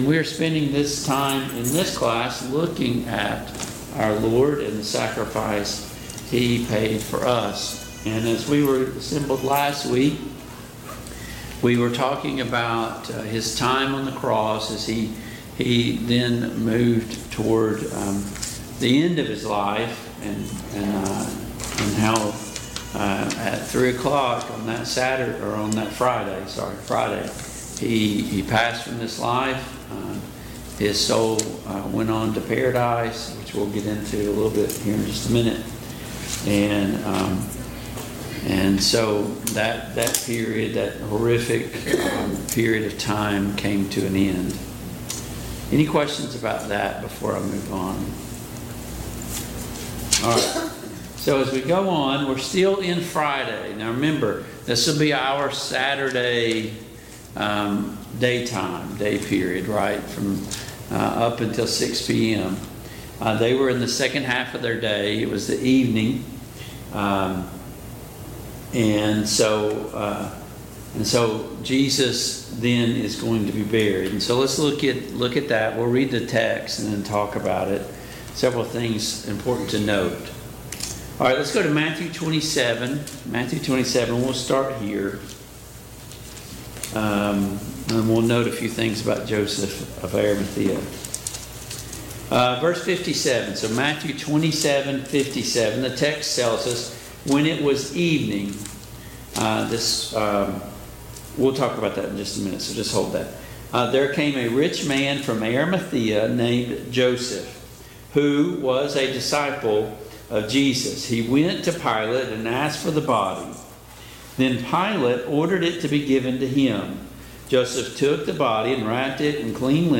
Biblical Timeline of Jesus' Resurrection Service Type: Mid-Week Bible Study Download Files Notes Topics: The Burial of Jesus « 7.